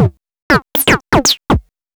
SYNTH_3_L.wav